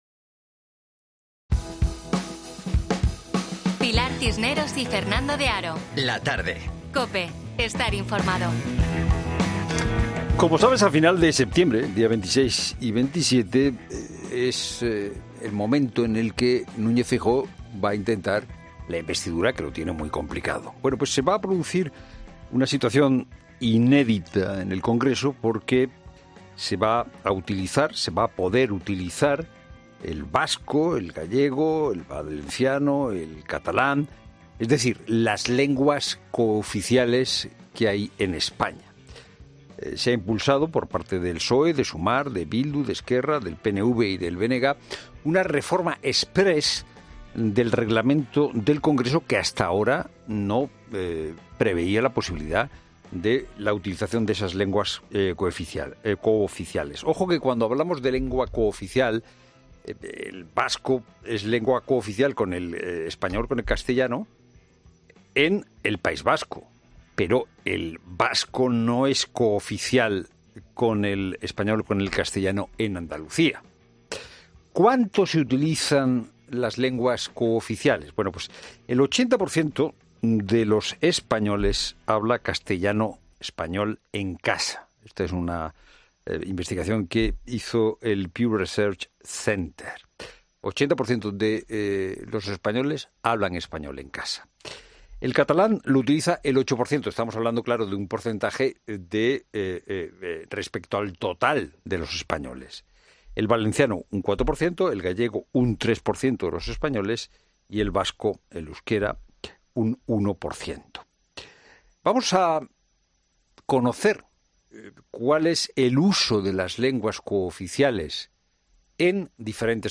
Hoy nos ha acompañado en La Tarde de COPE